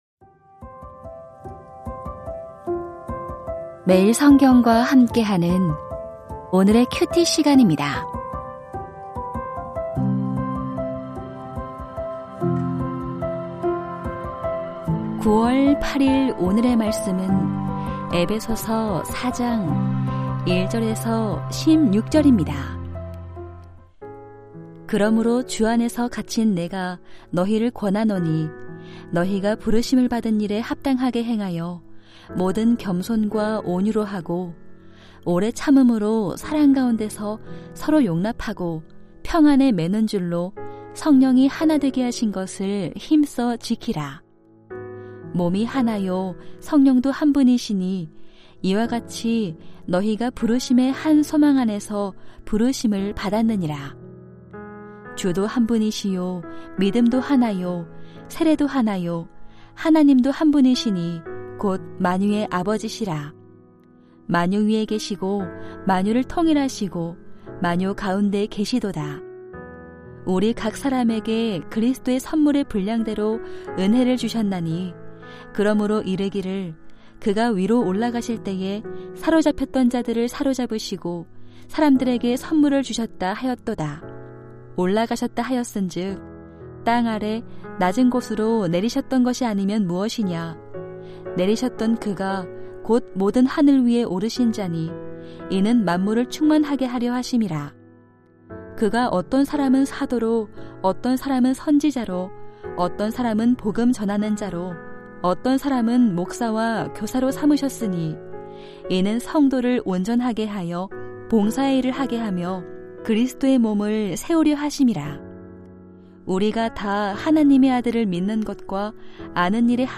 에베소서 4:1-16 하나 되게 하시는 하나님 2022-09-08 (목) > 오디오 새벽설교 말씀 (QT 말씀묵상) | 뉴비전교회